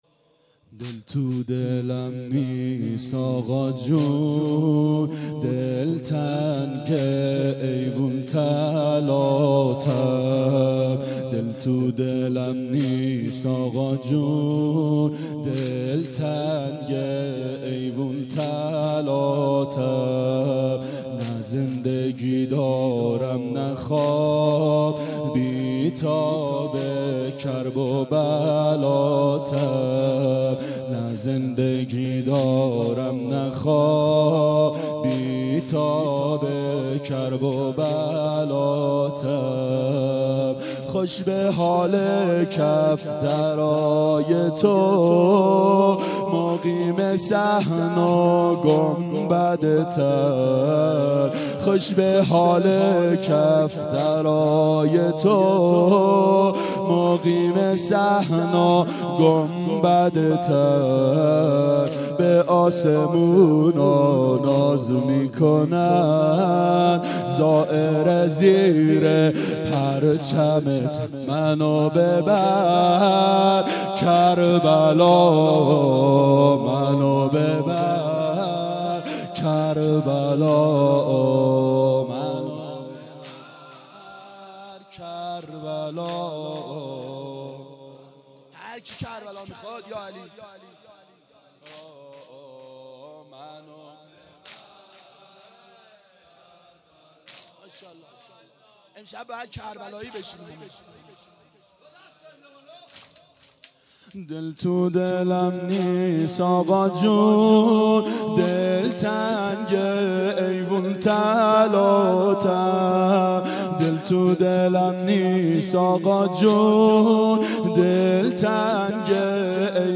شب عاشورا 1391 هیئت عاشقان اباالفضل علیه السلام